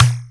SI2 BONG.wav